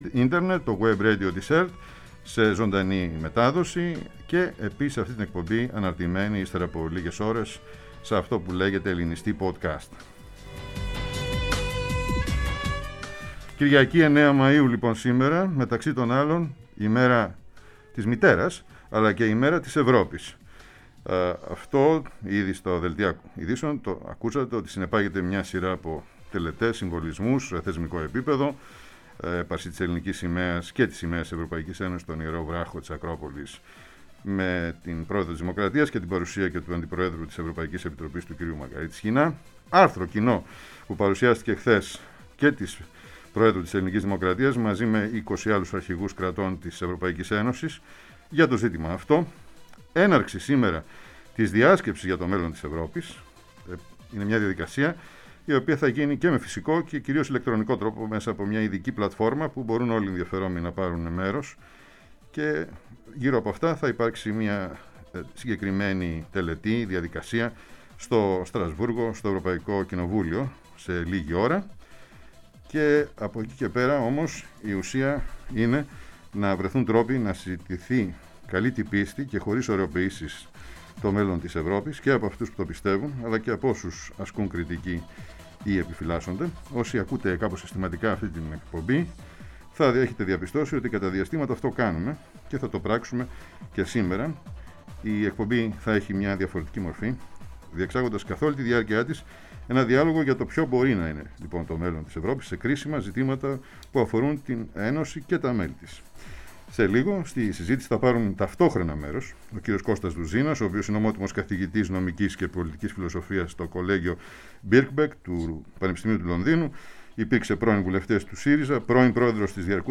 Ποιo είναι το μέλλον για την Ευρώπη; Διάλογος Κώστα Δουζίνα